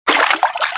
00024_Sound_splash.mp3